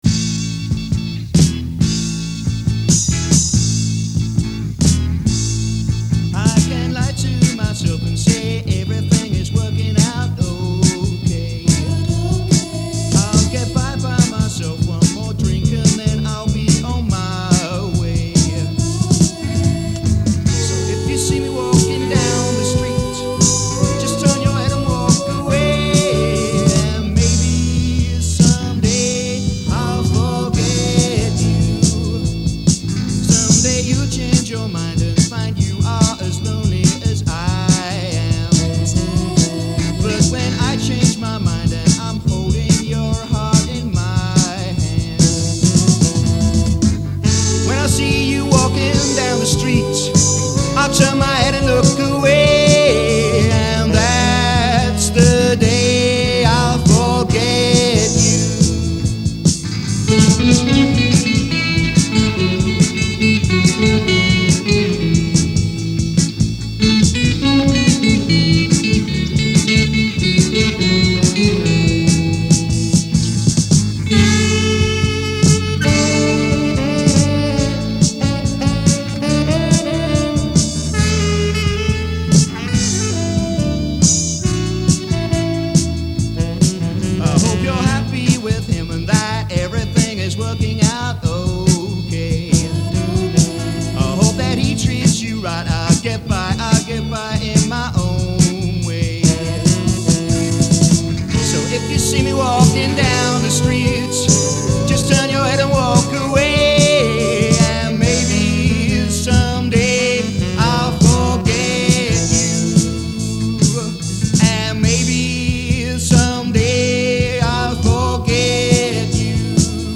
Popular on the Brighton scene in the early eighties, THE FASCINATIONS were an eight piece soul, r&b pop band that was formed from the remnants of the
Also, eight tracks in mono.
Recorded at SOUND SENSE STUDIOS, SEAFORD, EAST SUSSEX, UK.